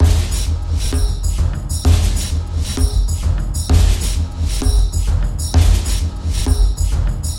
描述：未知类型/ 130 bpm,percussive groove。
Tag: 130 bpm Cinematic Loops Percussion Loops 1.24 MB wav Key : Unknown